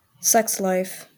Ääntäminen
Ääntäminen US Tuntematon aksentti: IPA : /ˈsɛksˌlaɪf/